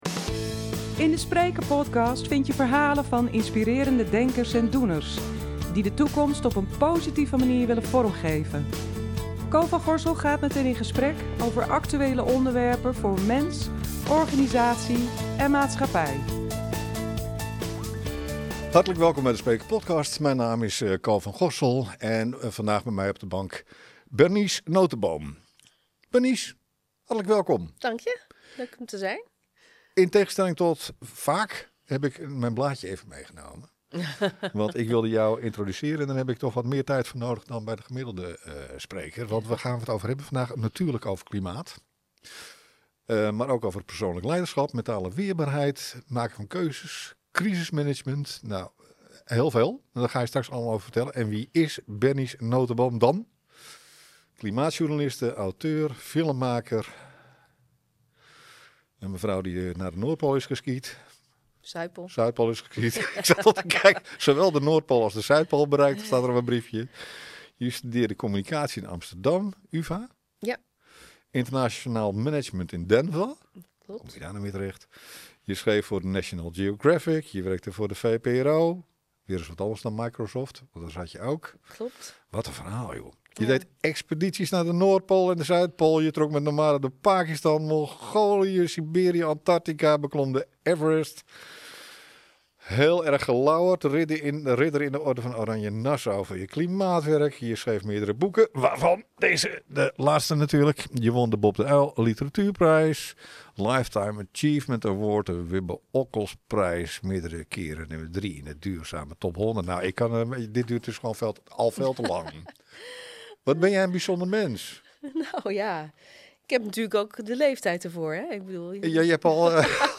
Een persoonlijk en krachtig gesprek over verandering, leiderschap en de rol van je eigen kompas, of je nu op expeditie bent of midden in een organisatie staat.